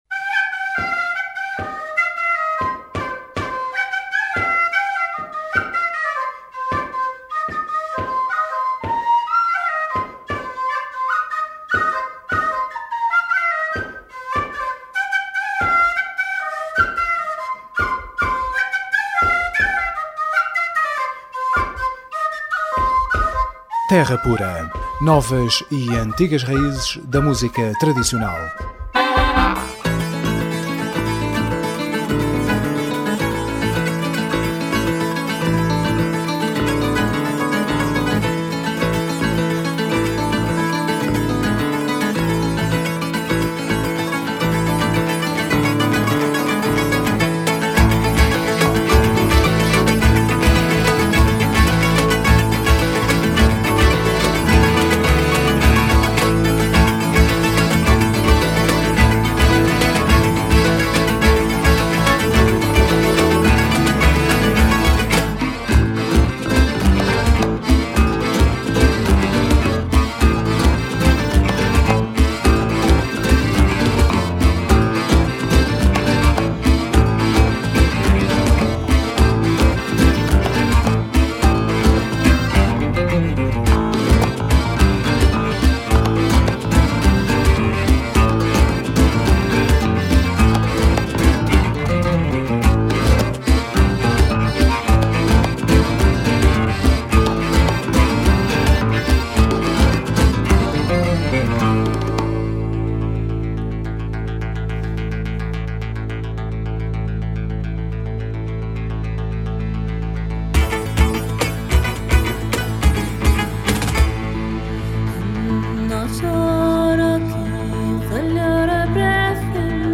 Terra Pura 18MAI12: Entrevista Mu